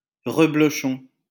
Reblochon (French pronunciation: [ʁəblɔʃɔ̃]